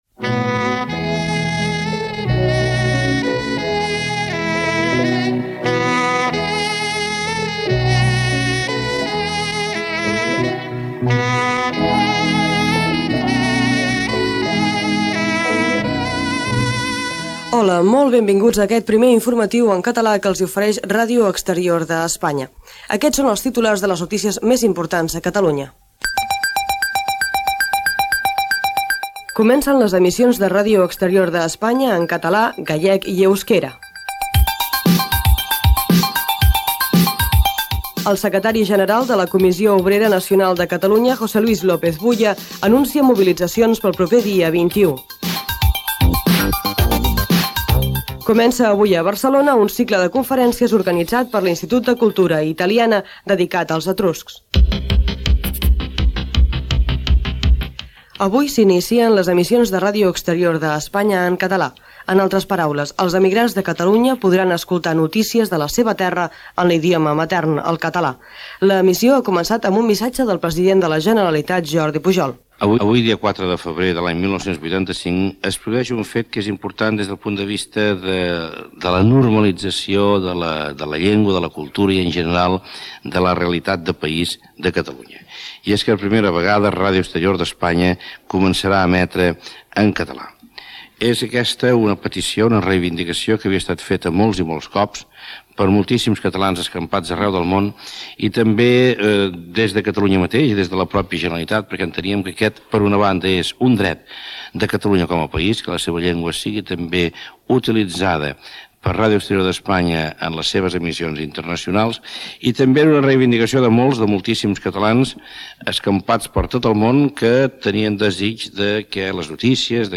Sintonia de l'espai, sumari informatiu, informació de l'inici de les emissions en català de REE, missatge del president de la Generalitat Jordi Pujol
Informatiu